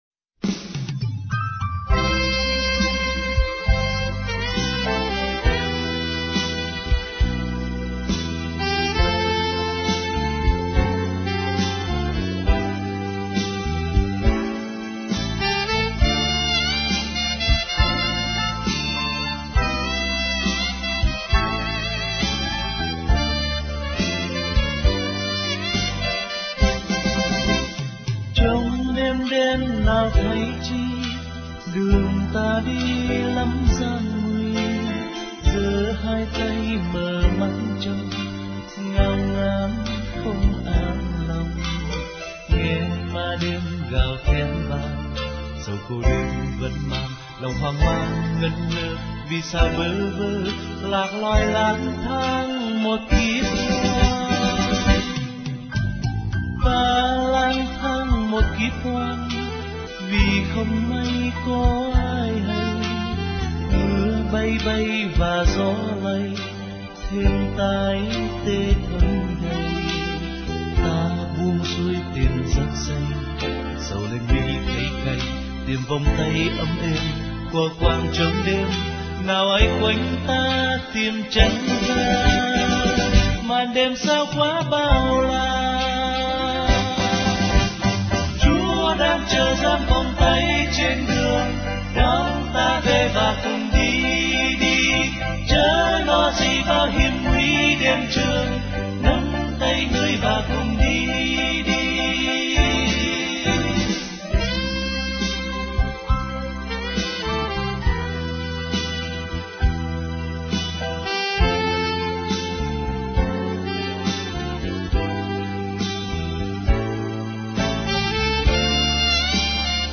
* Thể loại: Ngợi ca Thiên Chúa